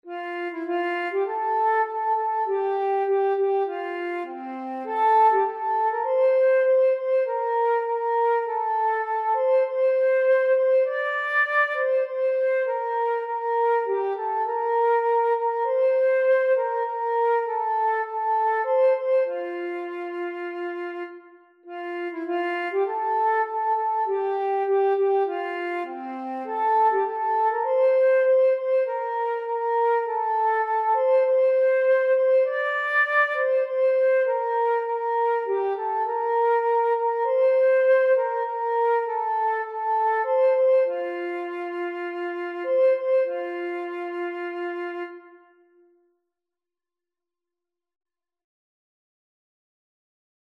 Een kringspel voor het Sint Jansfeest